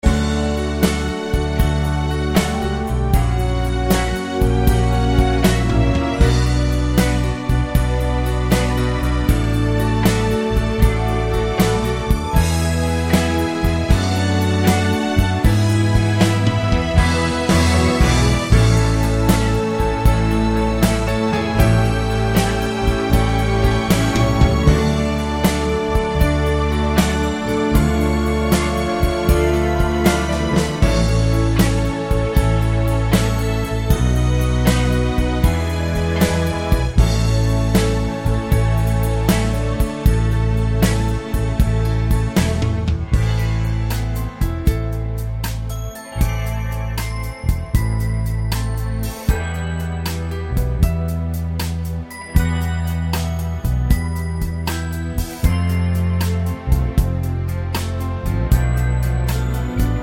no Backing Vocals Crooners 3:33 Buy £1.50